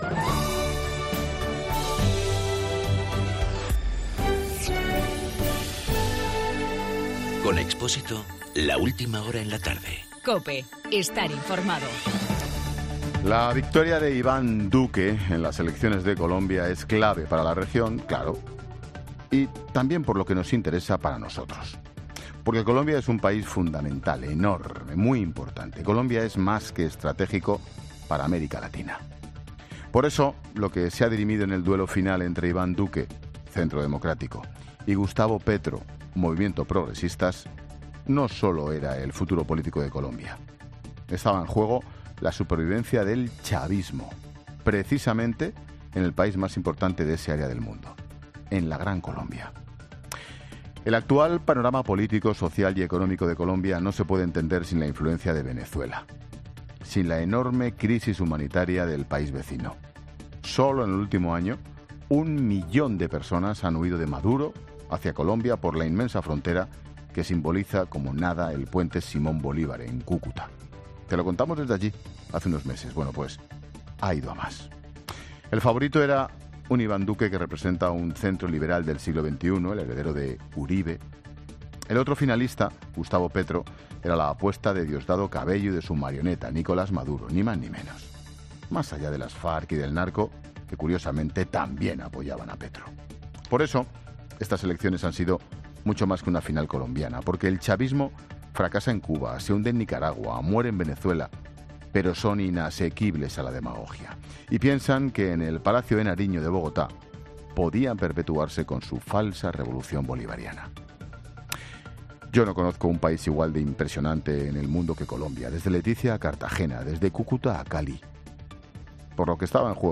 Monólogo de Expósito
El comentario de Ángel Expósito sobre las elecciones en Colombia.